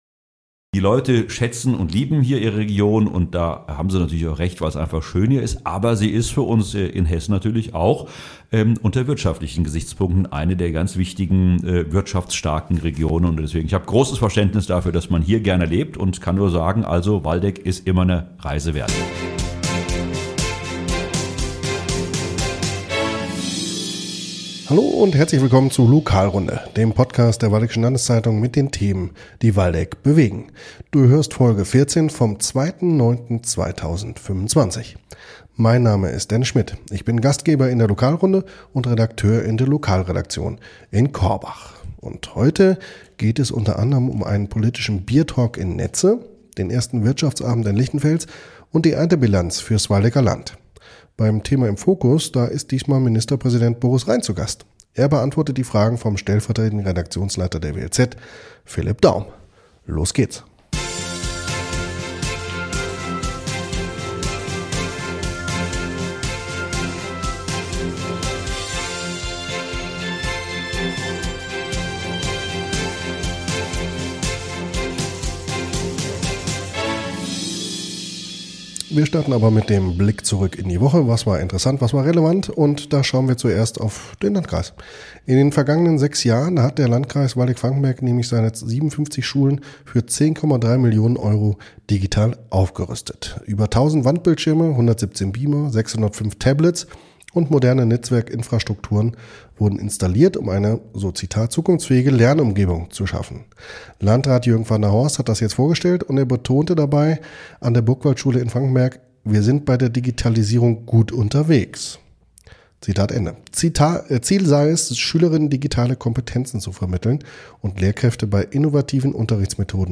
übergibt beim „Thema im Fokus“ diesmal das Mikro für ein Interview mit Hessens Ministerpräsident Boris Rhein.